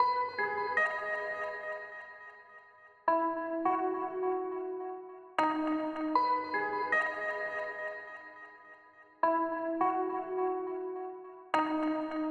Citrus_Pluck.wav